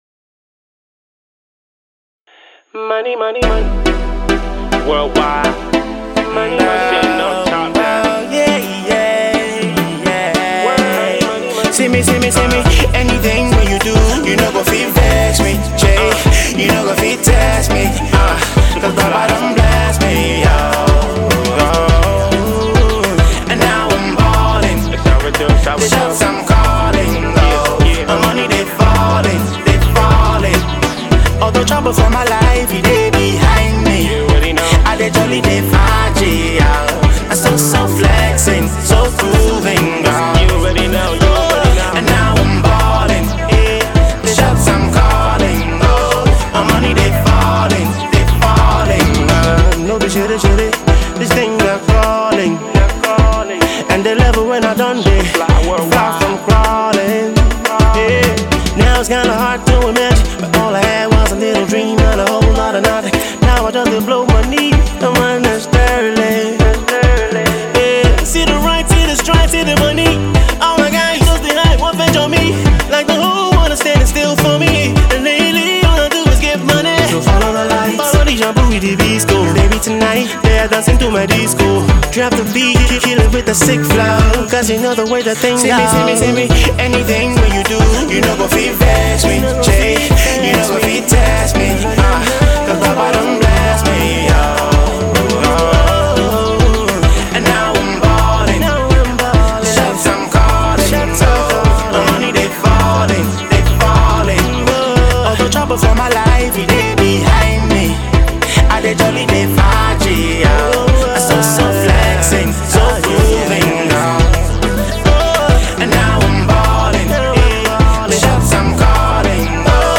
a rare, distinct style of Nigerian R&B